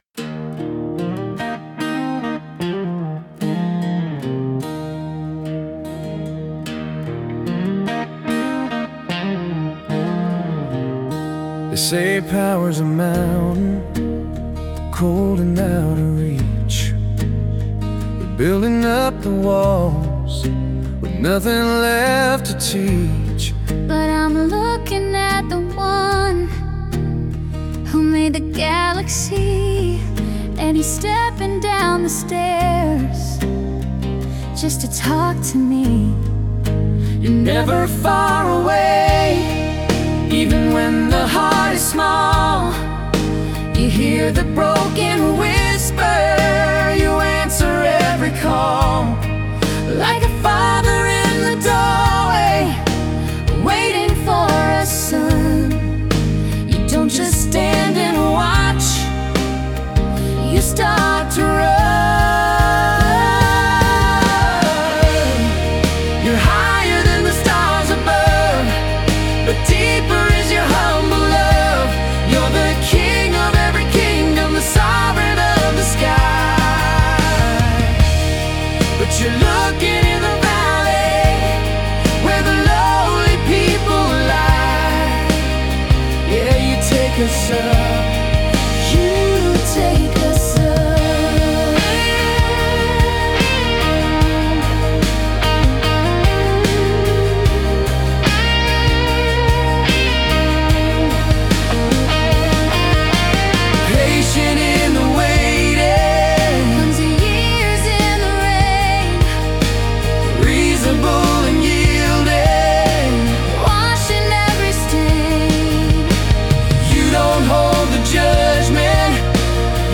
My AI Created Music